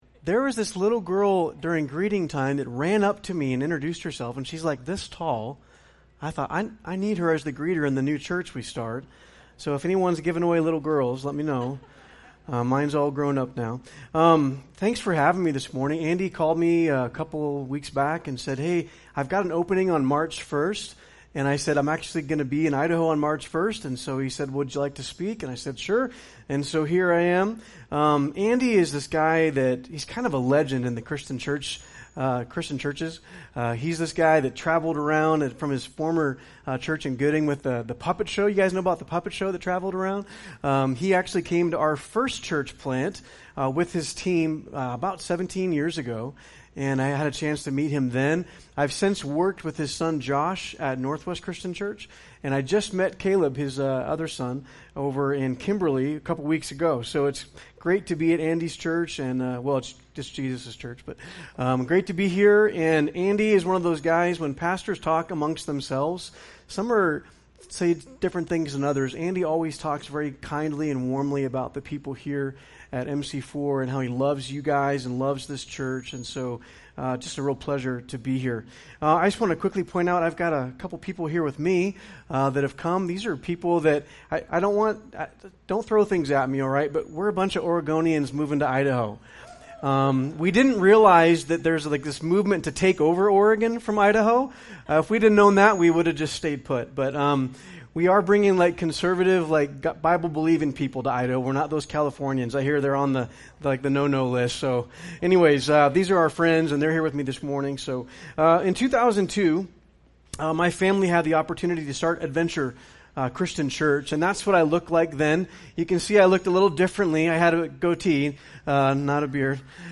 SERMONS «